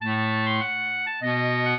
clarinet
minuet8-12.wav